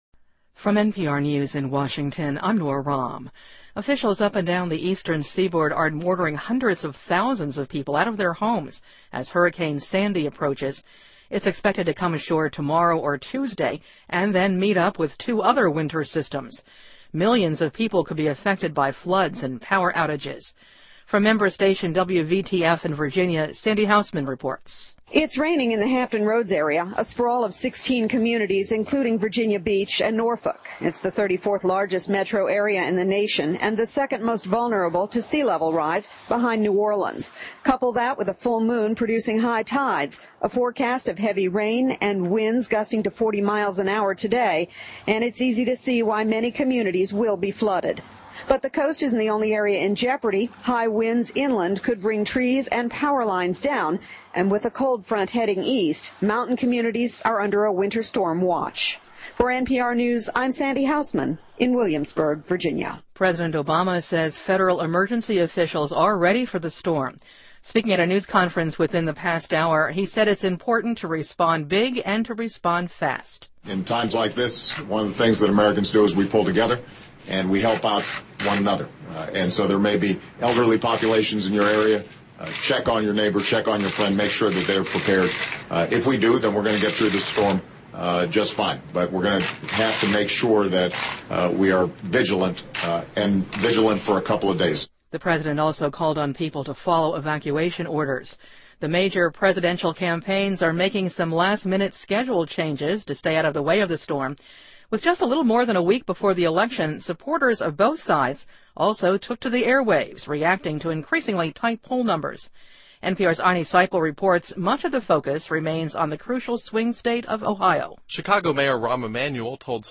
NPR News,飓风桑迪搅局美总统大选